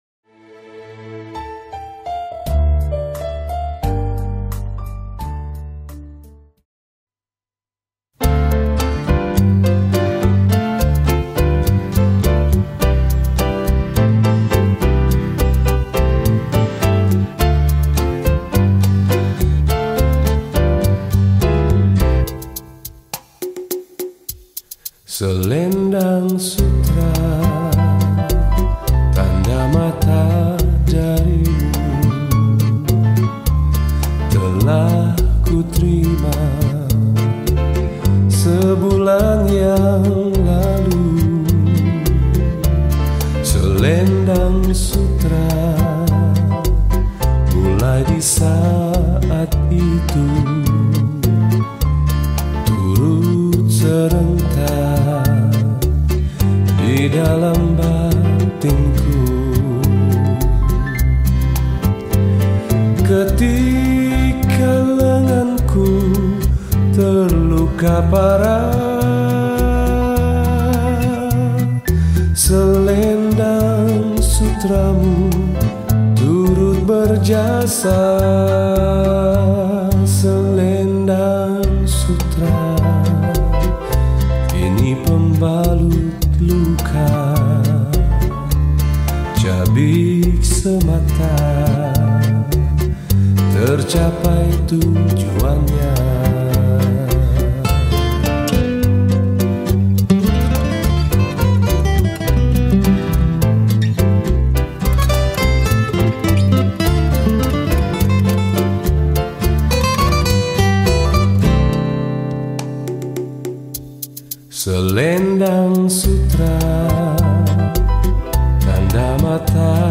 Patriotic Songs
Skor Angklung